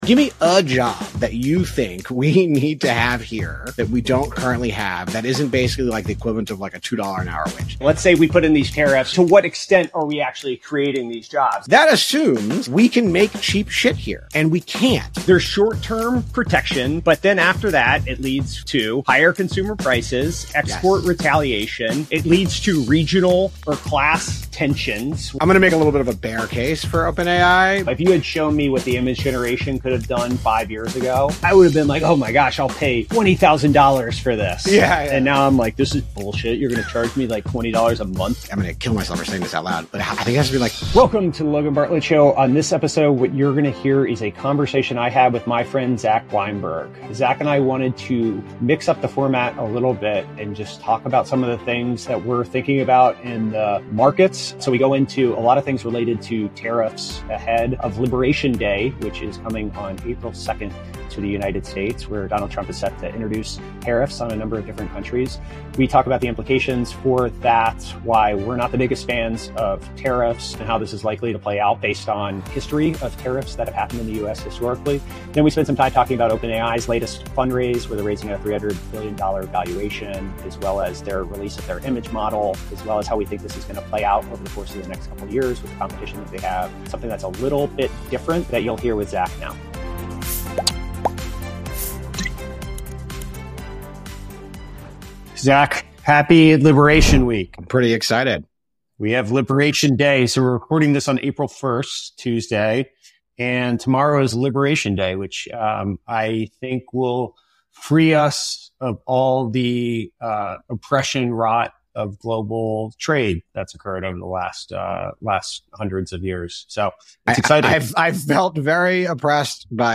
They banter through the core arguments for and against tariffs, including national security, domestic employment, and negotiation power.